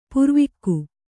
♪ purvikku